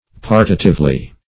partitively - definition of partitively - synonyms, pronunciation, spelling from Free Dictionary Search Result for " partitively" : The Collaborative International Dictionary of English v.0.48: Partitively \Par"ti*tive*ly\, adv.